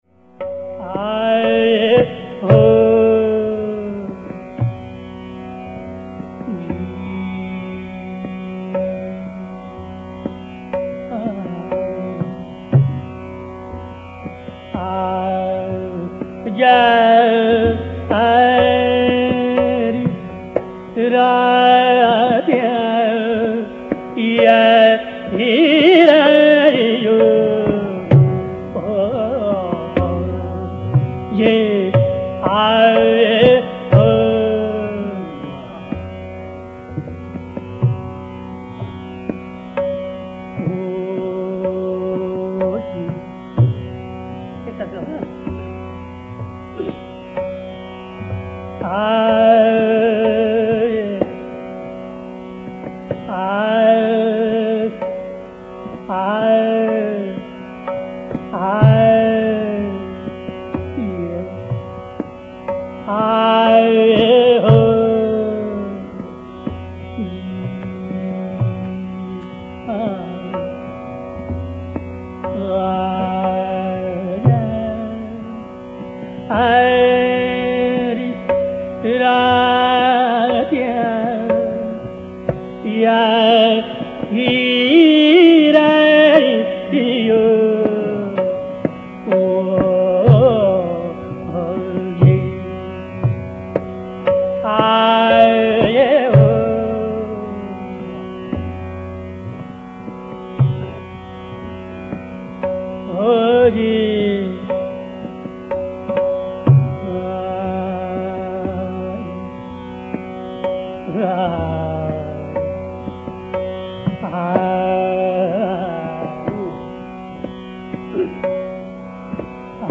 Kumar Gandharva laces his Malkauns with (at least) a couple of quaint touches. The measured g–>S meend emits an abhasa (impression, semblance) of the rishab.